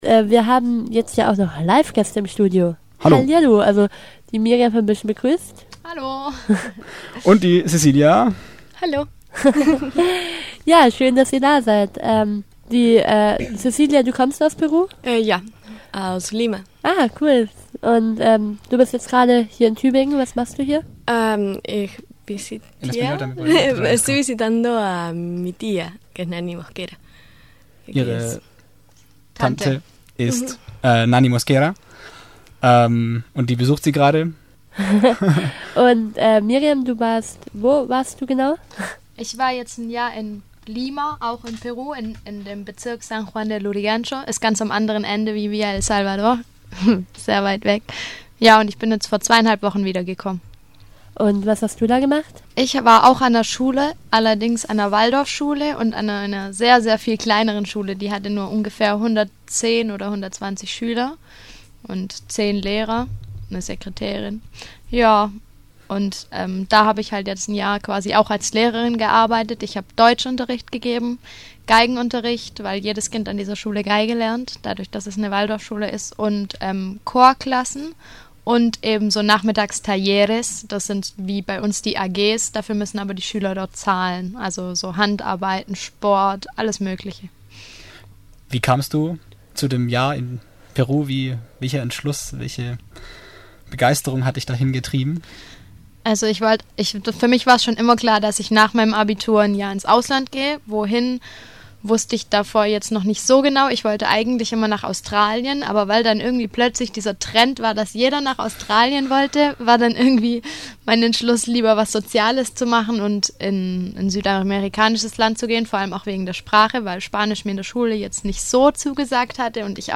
FSJlerinnen im Interview